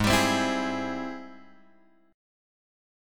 G# Augmented Major 7th